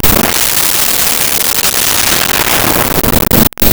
Crowd Gasp
Crowd Gasp.wav